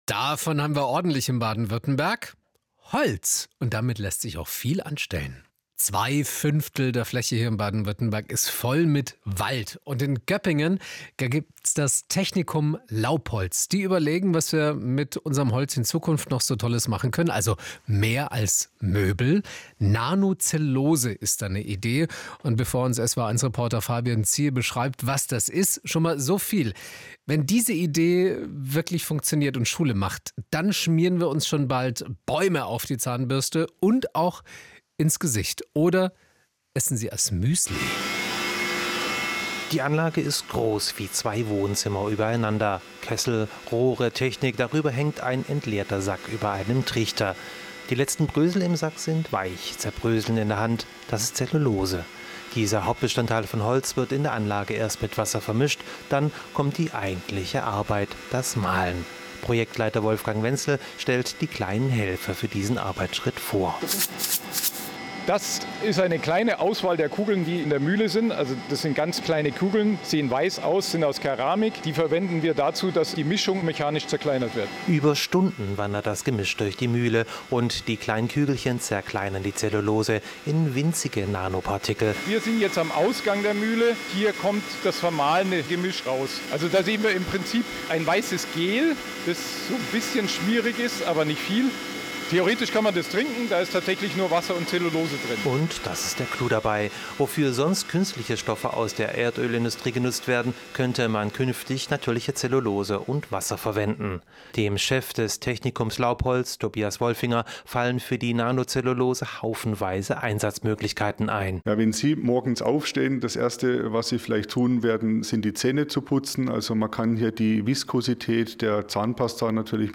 SWR1 Radiobeitrag
Beitrag-Nanocellulose-SWR1.wav